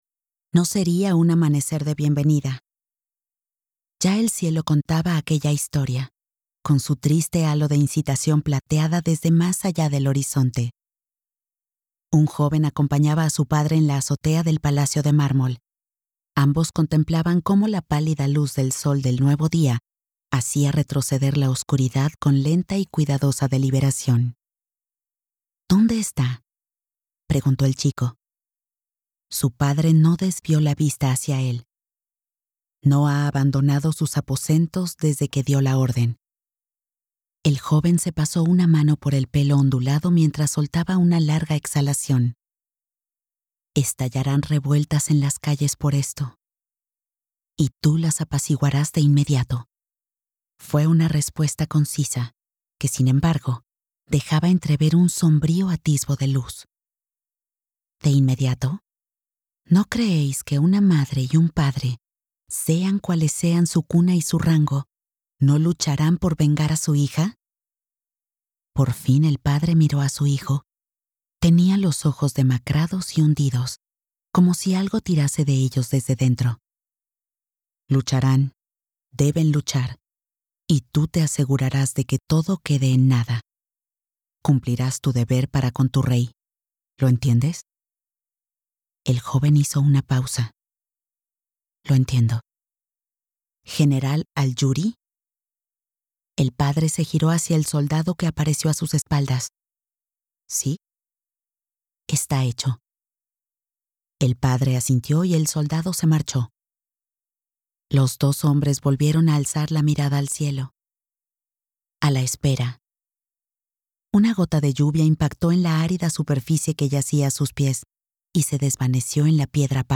Audiolibro La ira y el amanecer (The Wrath and the Dawn)